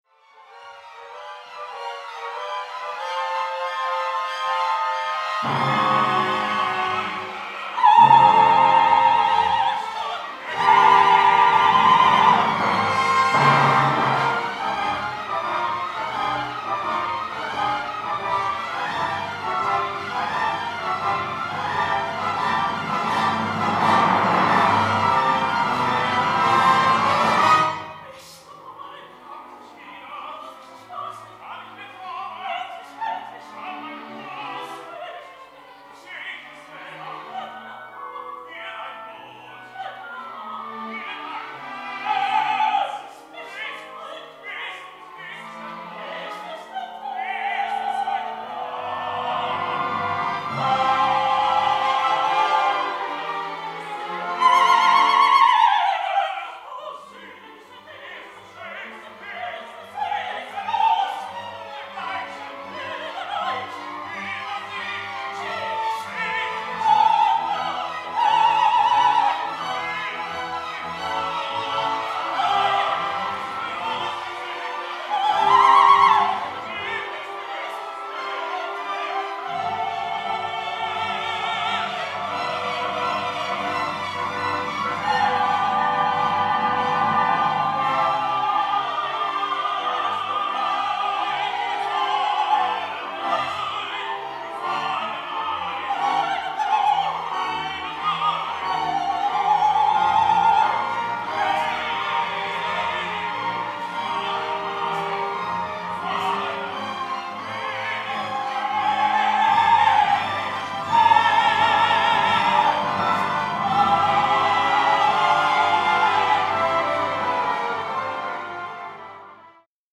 Dramatischer Sopran
Diese Hörproben sind Live-Mitschnitte durch Bühnenmikrophone, stellen also keine Studioqualität dar und sollen lediglich einen Stimm- und Interpretationseindruck vermitteln.